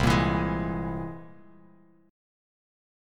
Listen to C+7 strummed